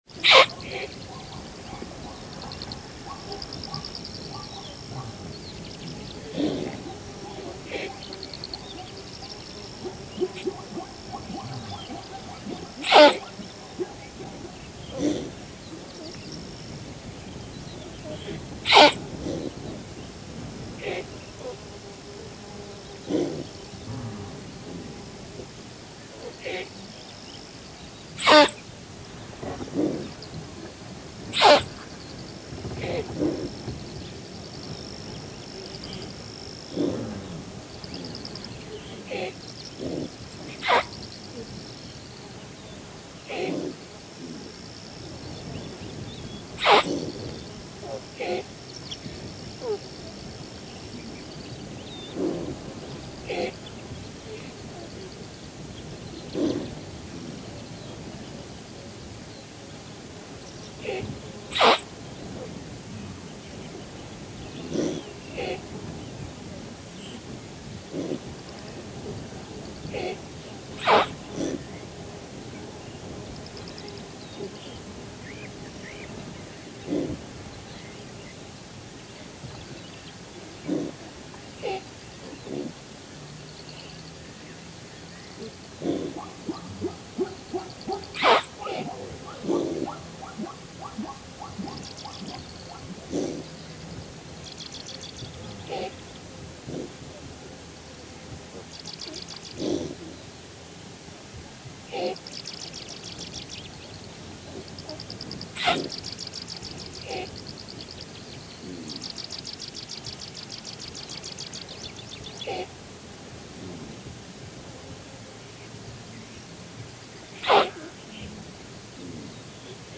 Звуки газели
Тревожный зов газели Томпсона